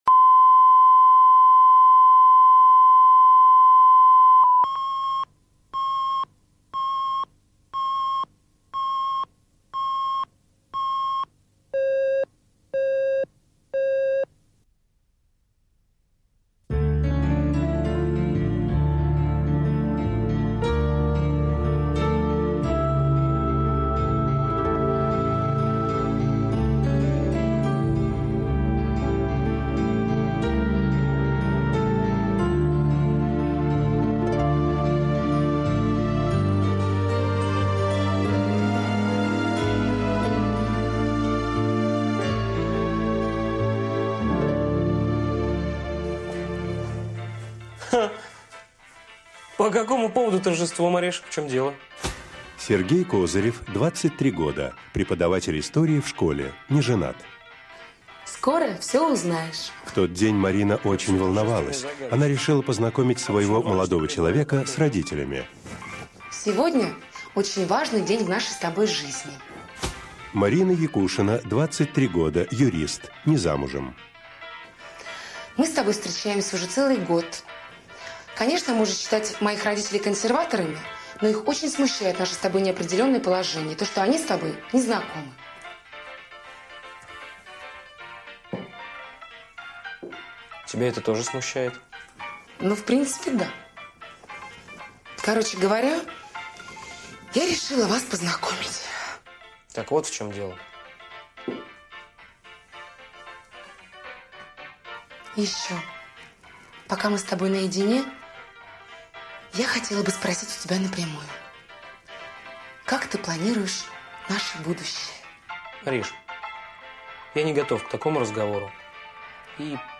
Аудиокнига Капитан | Библиотека аудиокниг